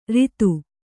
♪ ritu